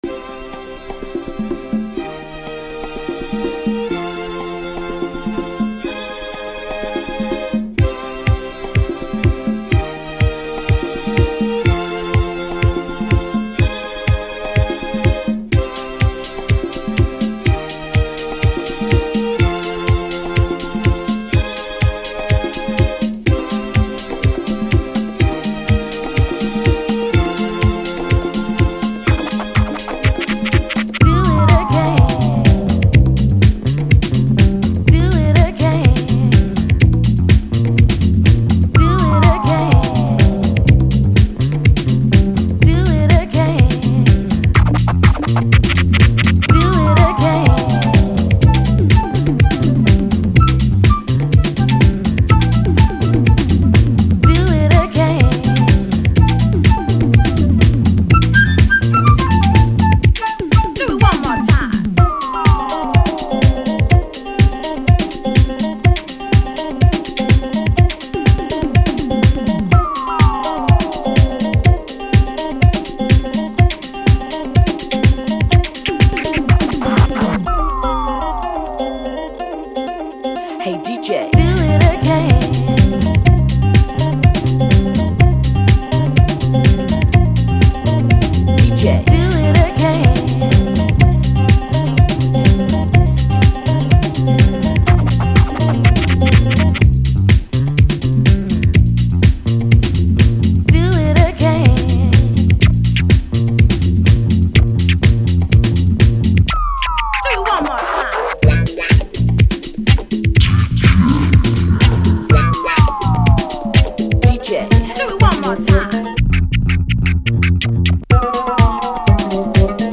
It's a fusion of arabic music and disco house
diva vocals